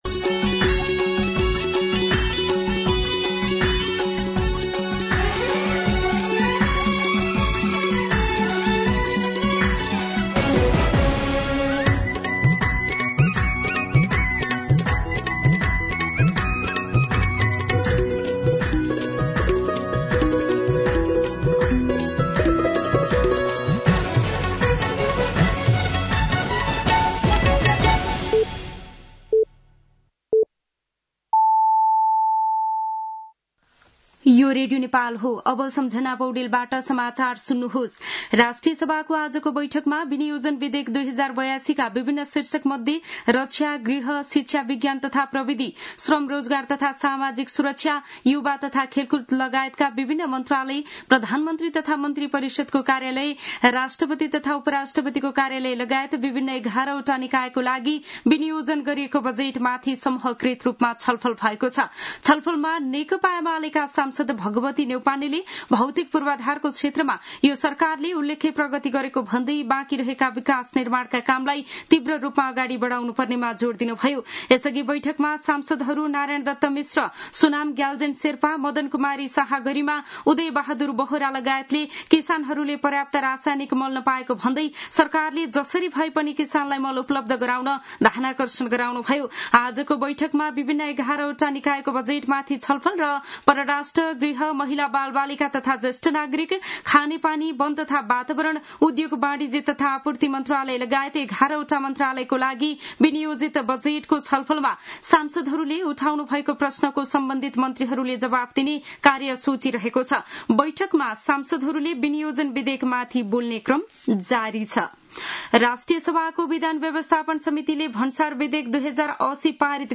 दिउँसो १ बजेको नेपाली समाचार : १७ असार , २०८२